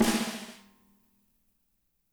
-MEDSNR2E -L.wav